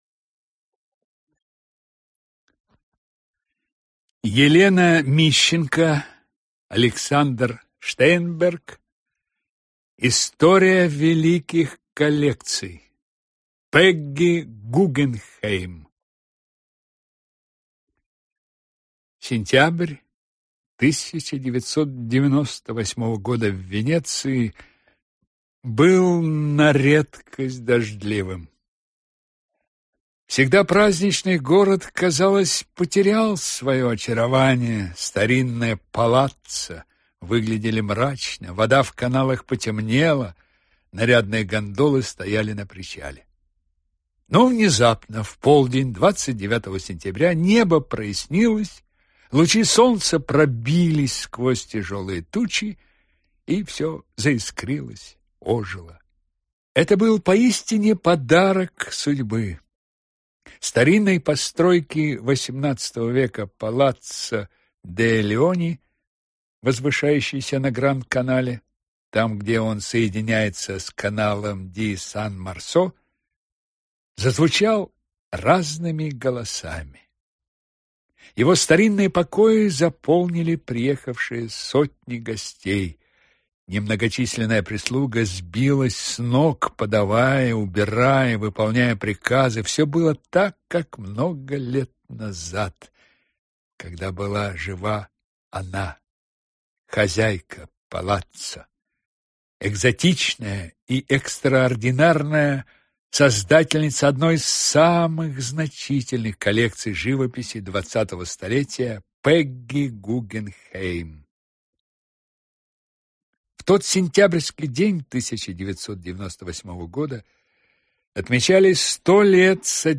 Аудиокнига История великих коллекций. Пегги Гуггенхейм | Библиотека аудиокниг